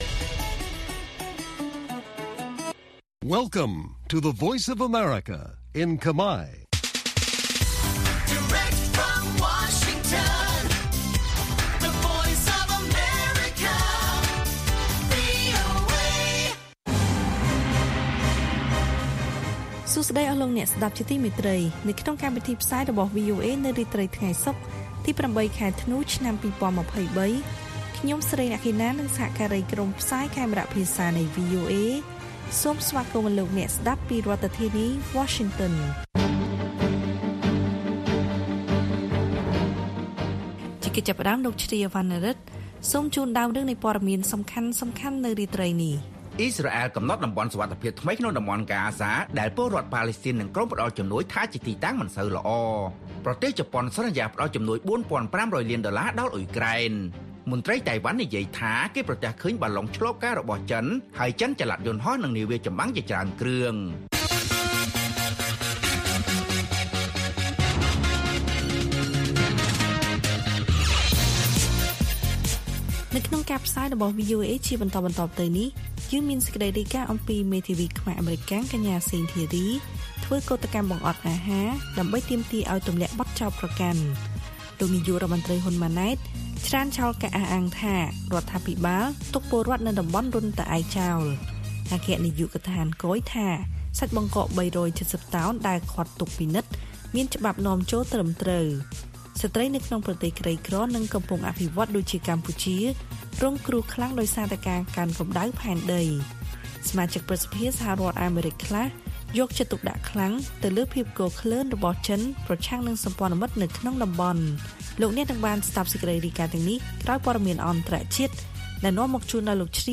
ព័ត៌មានពេលរាត្រី ៨ ធ្នូ៖ មេធាវីខ្មែរអាមេរិកាំងកញ្ញា សេង ធារី ធ្វើកូដកម្មបង្អត់អាហារ ដើម្បីទាមទារឱ្យទម្លាក់បទចោទប្រកាន់